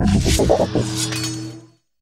Cri de Scalpereur dans Pokémon HOME.